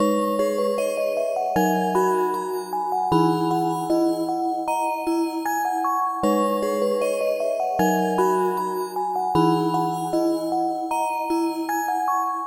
未来型钟声的旋律卡通片
Tag: 154 bpm Trap Loops Bells Loops 2.10 MB wav Key : F FL Studio